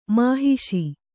♪ māhiṣi